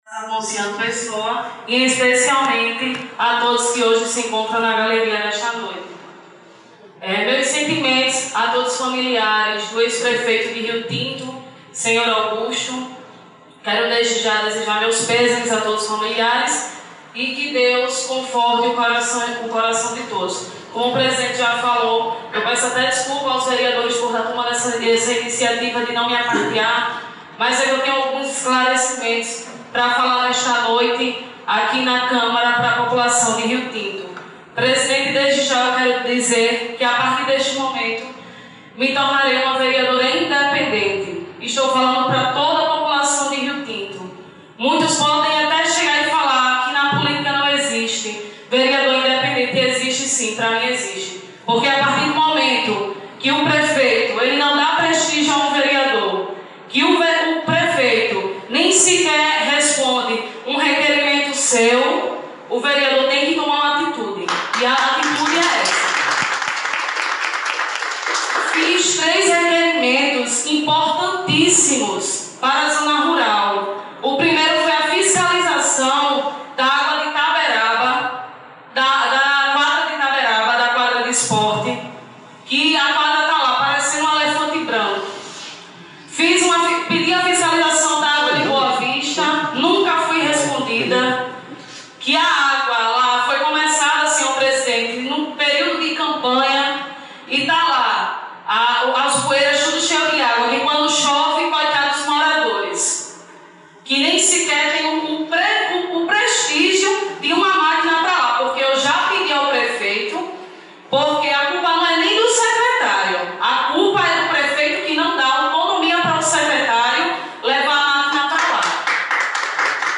Vereadora Amanda Lima (PSB)
O pronunciamento aconteceu durante sessão na Câmara dos Vereadores de Rio Tinto. Ela colocou os cargos indicados na gestão à disposição do prefeito.
Sob aplausos das pessoas presentes na galeria, Amanda fez críticas à atual gestão durante o anúncio.